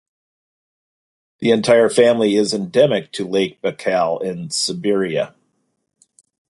Pronunciado como (IPA)
/baɪ.ˈkæl/